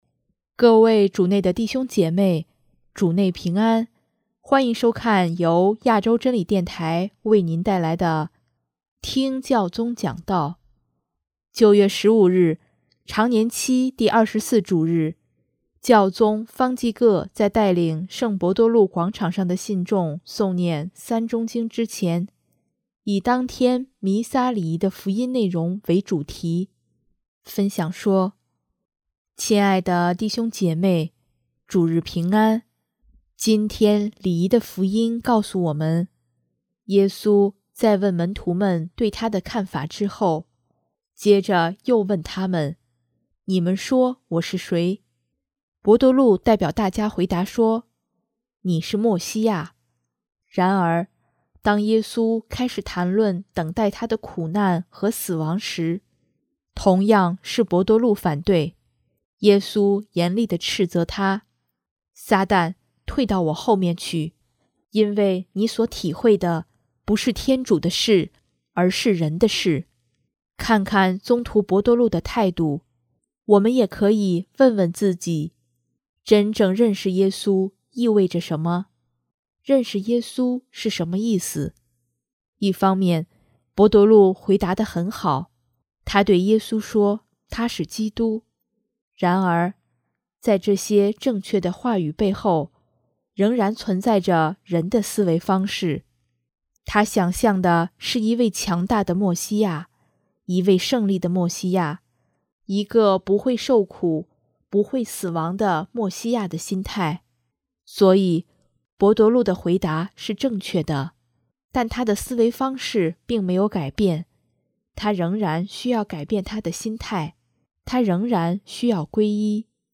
首页 / 新闻/ 听教宗讲道
9月15日，常年期第二十四主日，教宗方济各在带领圣伯多禄广场上的信众诵念《三钟经》之前，以当天弥撒礼仪的福音内容为主题，分享说：